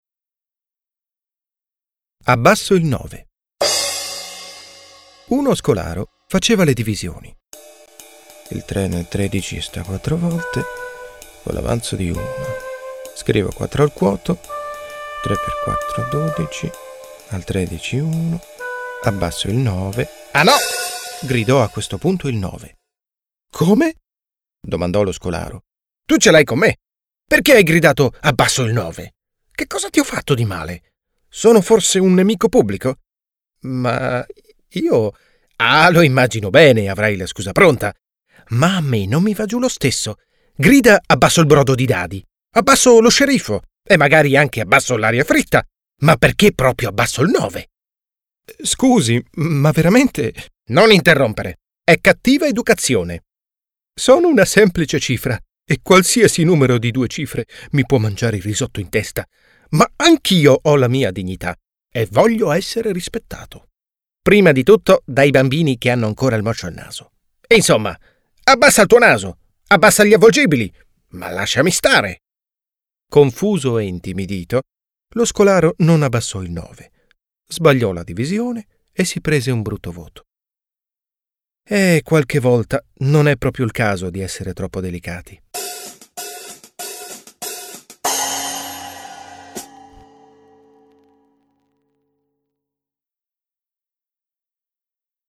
Smart young warm voice italienisch ohne Akzent Stimmalter: junger Erwachsener Stimmlage: Tenor, Alt
Sprechprobe: Sonstiges (Muttersprache):
Smart young warm voice perfect italian with no accent Commercials, Audio Ads, Documentaries, E-Learning, Comics, Songs, Dubbing, Games, Presentations, Podcasts/Internet, Telephone systems